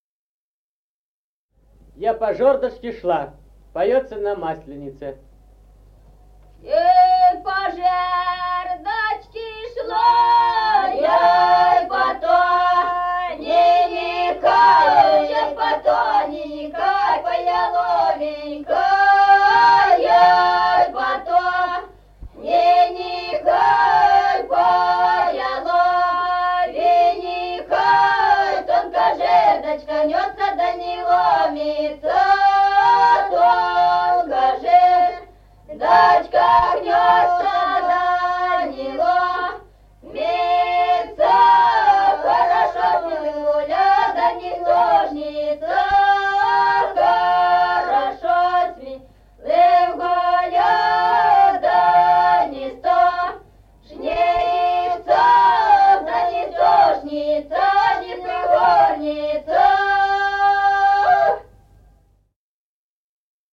Народные песни Стародубского района «Я по жердочке шла», масленичная.
с. Мишковка.